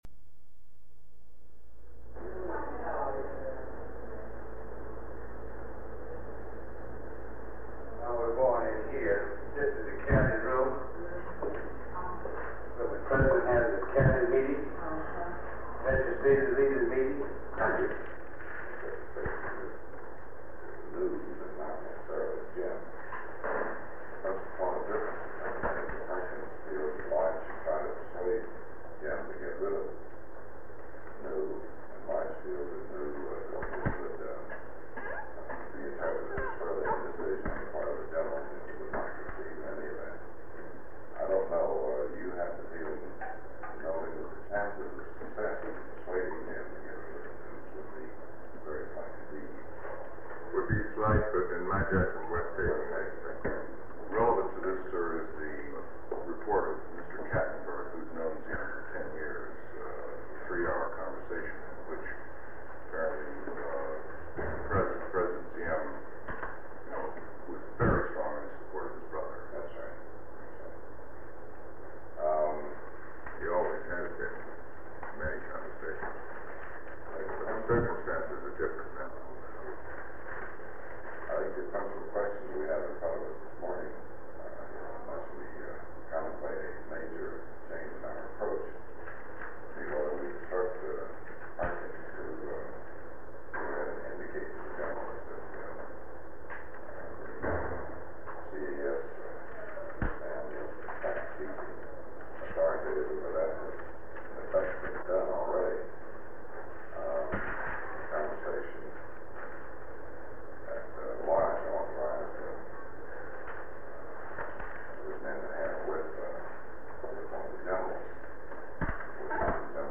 This meeting is a discussion between President Kennedy and a wide range of Department of State, Department of Defense, CIA, and White House advisors on the volatile situation in South Vietnam. Three segments of the recording totaling 1 minute and 44 seconds have been removed in accordance with Section 3.4 (b) (1), (3) of Executive Order 12958. This sound recording has been excerpted from Tape 108, which contains additional sound recording(s) preceding and following this one.
Secret White House Tapes | John F. Kennedy Presidency Meetings: Tape 108/A43.